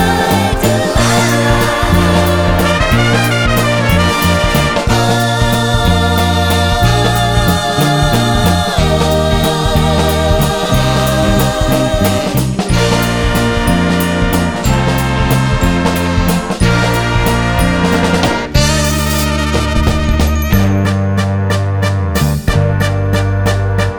Live Pop (1960s) 3:28 Buy £1.50